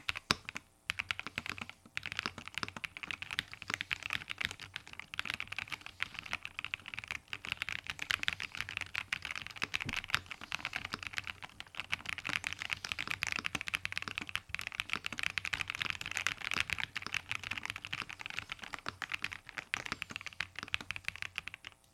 Sound Test
rt65sound.mp3